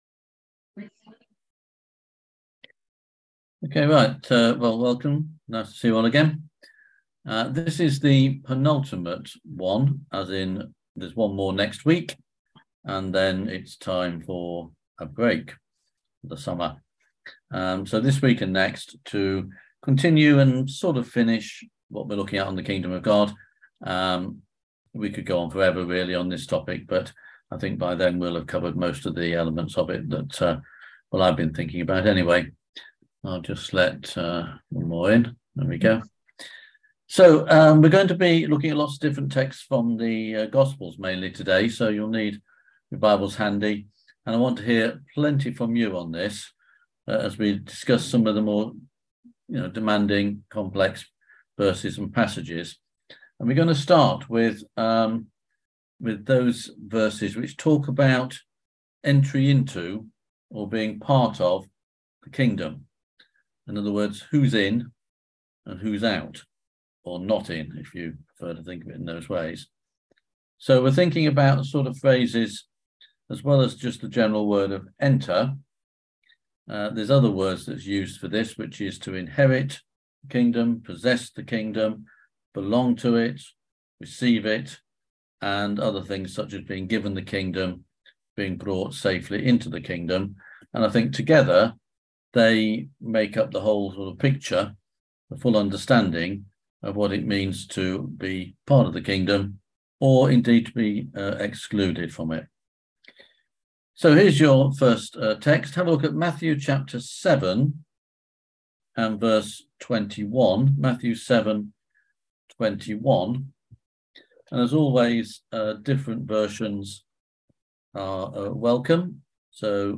On June 22nd at 7pm – 8:30pm on ZOOM